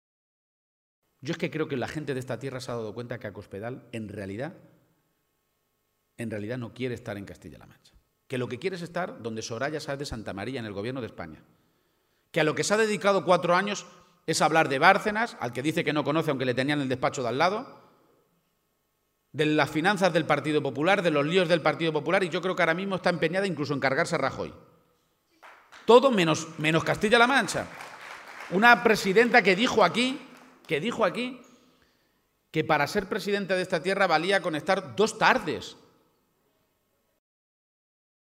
En un acto al que han asistido más de 300 personas en la localidad toledana de Miguel Esteban, García-Page ha pedido con mucha rotundidad que “antes que ser del PSOE, antes que ser de izquierdas o de derechas, seáis de esta tierra”.